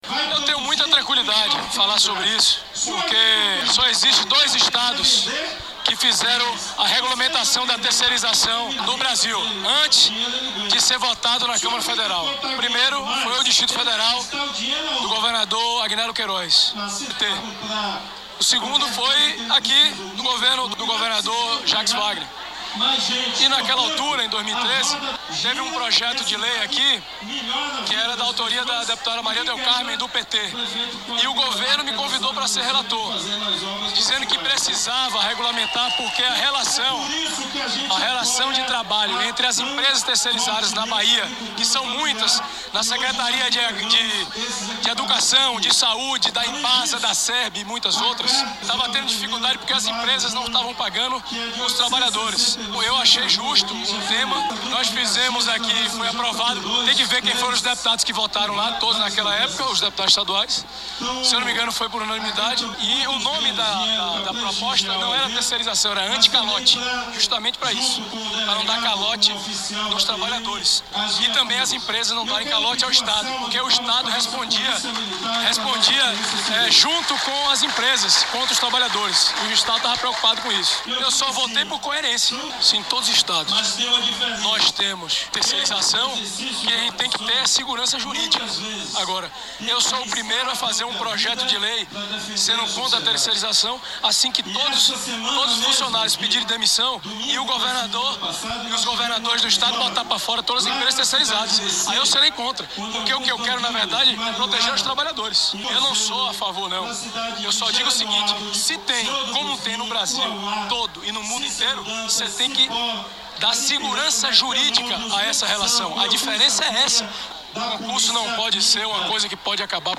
No espaço de aproximadamente 24 horas, dois deputados federais foram vaiados durante seus pronunciamentos em atos com a presença do
O progressista teve dificuldade de iniciar seu pronunciamento e a todo instante apelava para a multidão, pedindo paciência e que no final falaria sobre o assunto.